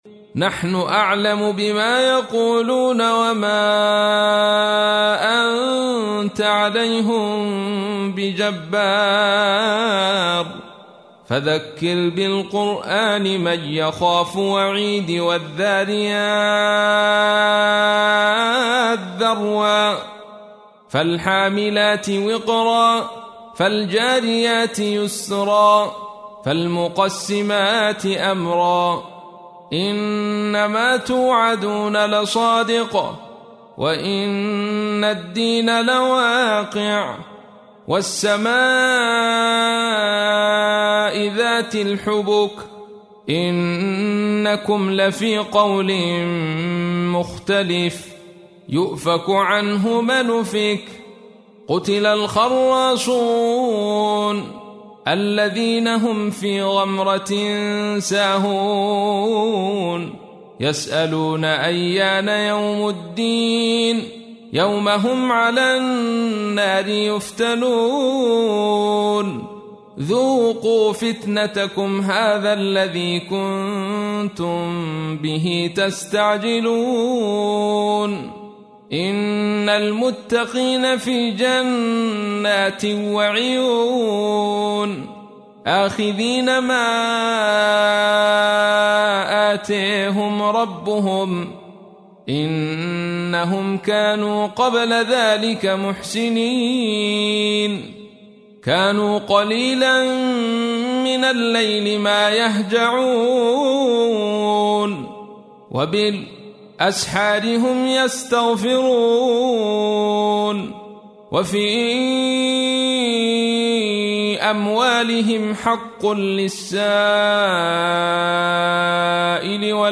تحميل : 51. سورة الذاريات / القارئ عبد الرشيد صوفي / القرآن الكريم / موقع يا حسين